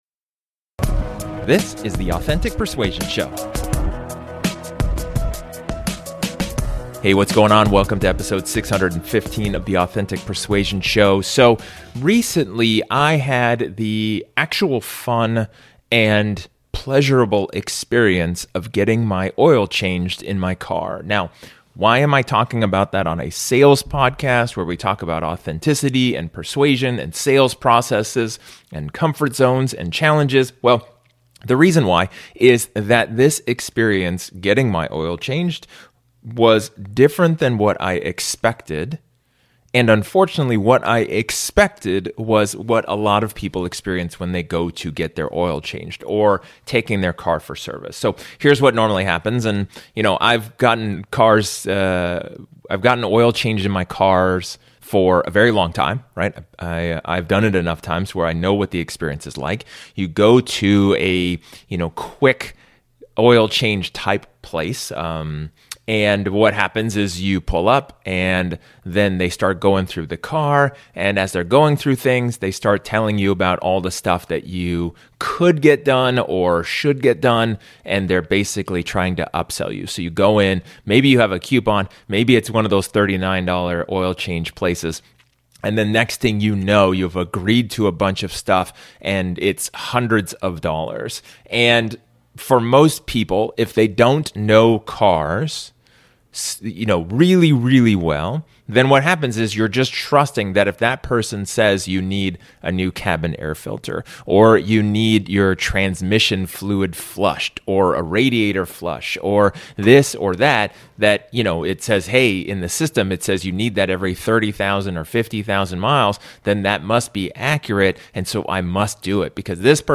In this solo episode, I talk about the lessons about sales that I learned from getting my oil changed.